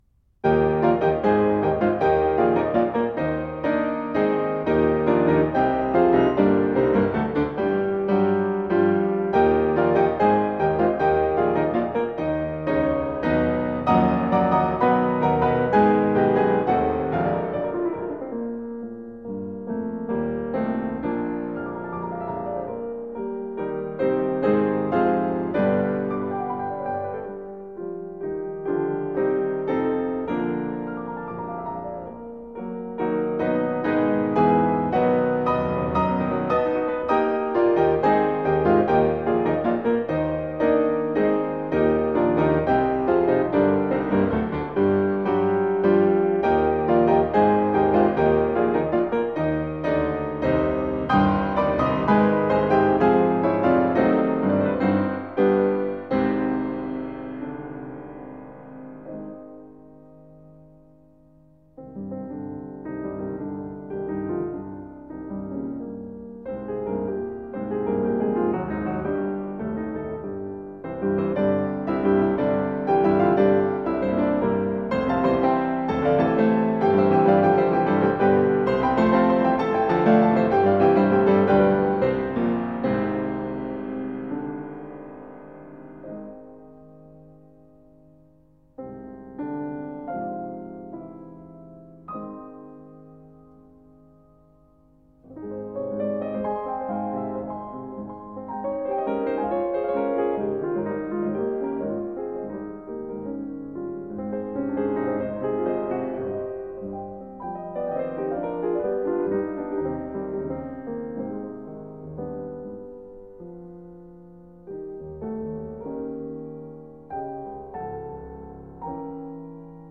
Classical Brahms, Johannes Rhapsody, Eb Major Op. 119 no.4 Piano version
Piano  (View more Intermediate Piano Music)
Classical (View more Classical Piano Music)